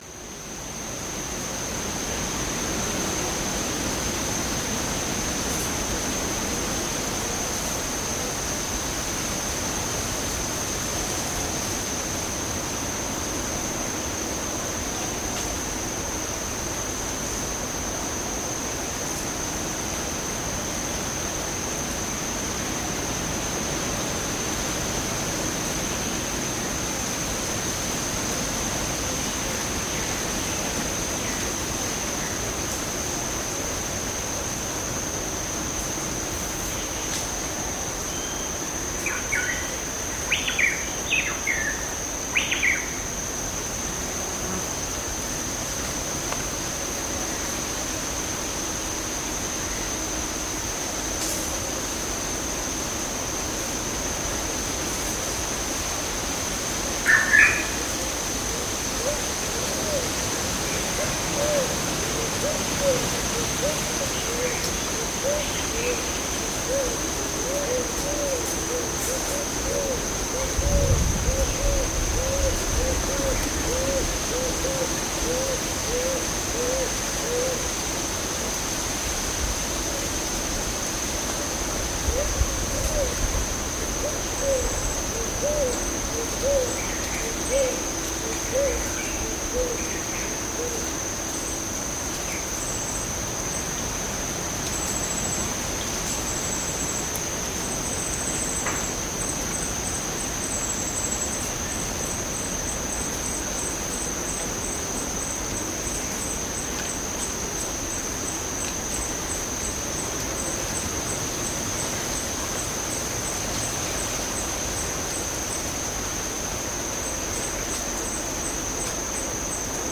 Шум пальм тропического леса и ветра